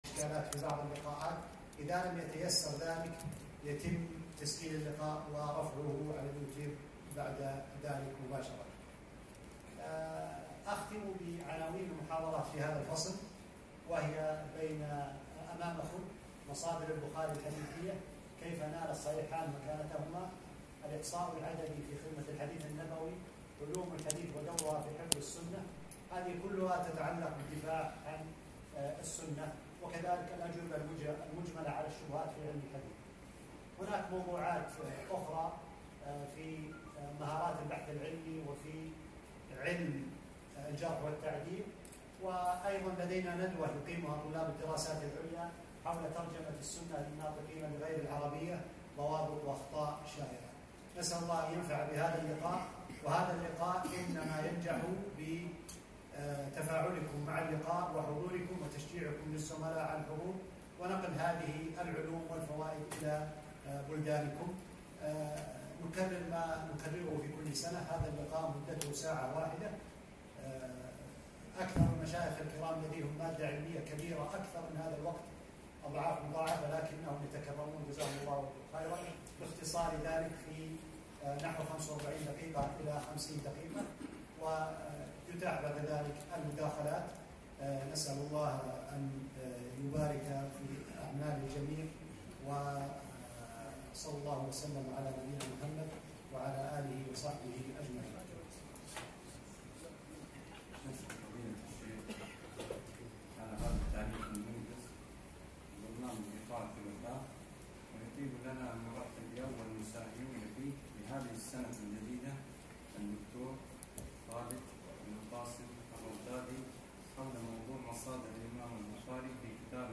محاضرة - مصادر الإمام البخاري في كتابه الجامع الصحيح